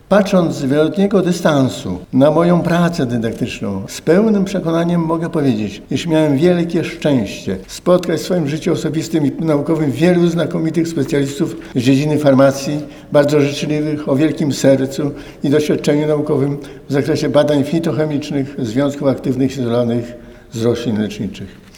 Uniwersytet Medyczny w Lublinie rozpoczął dziś dwudniowe obchody jubileuszu 80-lecia Wydziału Farmaceutycznego oraz 30-lecia nauczania Analityki Medycznej.